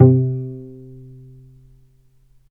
healing-soundscapes/Sound Banks/HSS_OP_Pack/Strings/cello/pizz/vc_pz-C3-mf.AIF at ae2f2fe41e2fc4dd57af0702df0fa403f34382e7
vc_pz-C3-mf.AIF